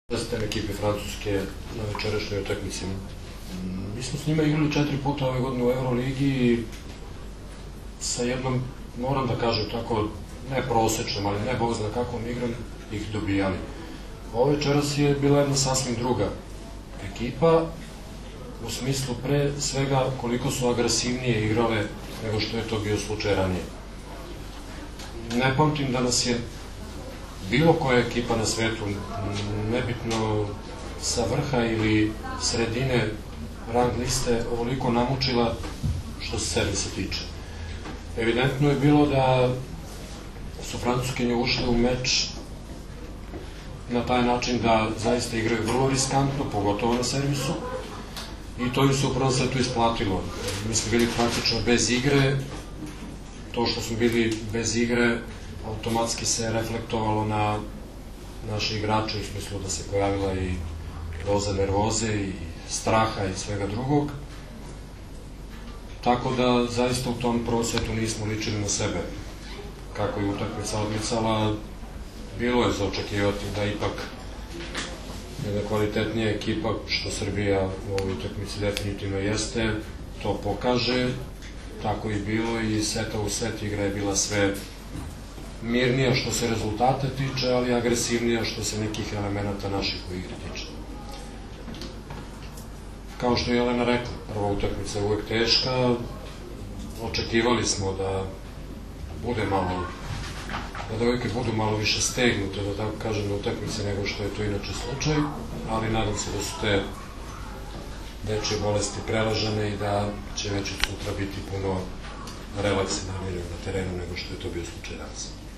IZJAVA ZORANA TERZIĆA